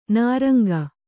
♪ nāraŋga